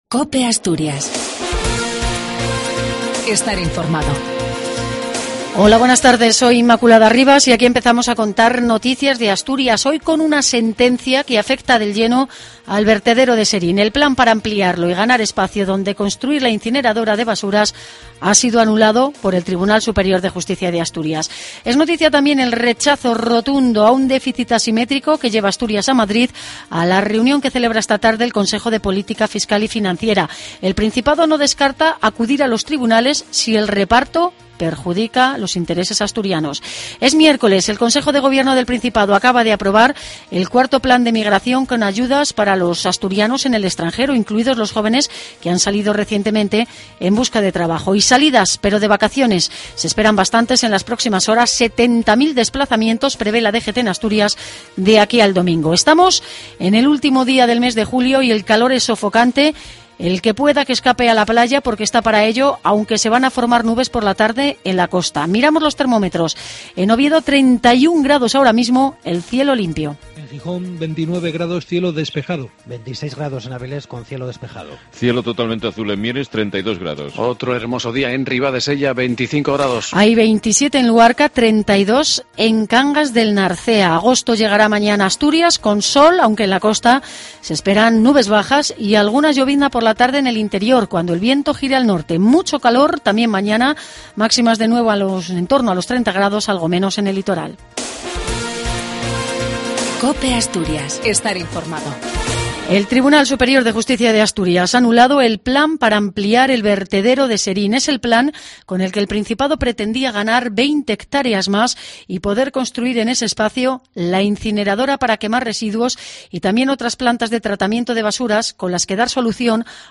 AUDIO: LAS NOTICIAS DE ASTURIAS Y OVIEDO AL MEDIODIA.